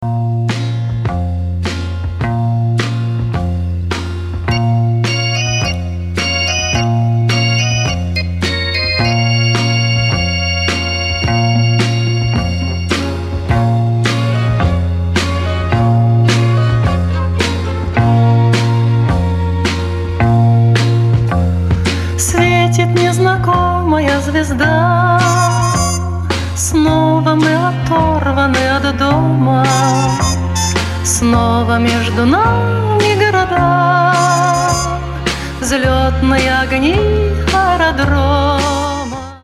ретро , романс